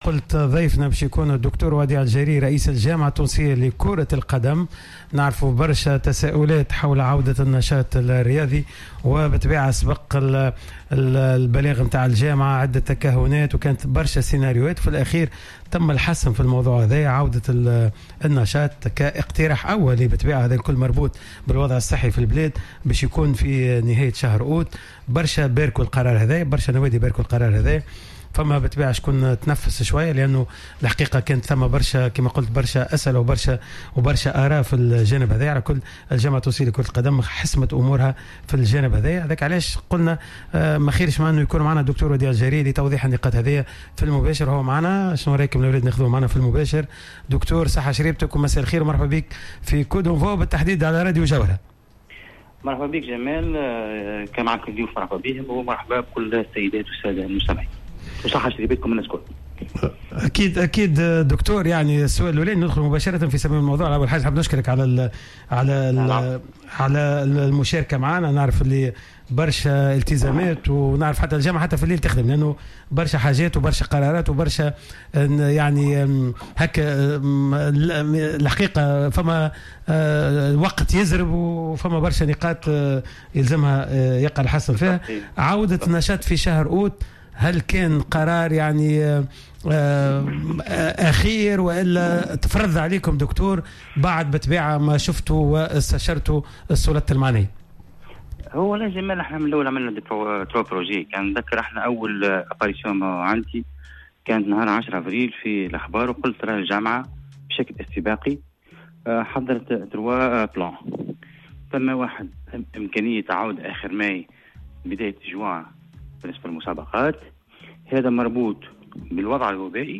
أكد رئيس الجامعة التونسية لكرة القدم الدكتور وديع الجريء خلال تدخله في برنامج coup d' envoi ان قرار استئناف نشاط بطولة الرابطة المحترفة الاولى خلال شهر اوت القادم قد كان بالتشاور مع وزارة الرياضة و الصحة و رئاسة الحكومة .